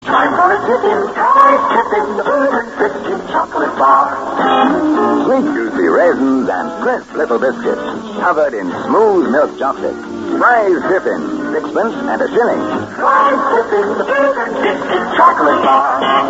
Tiffin advert.mp3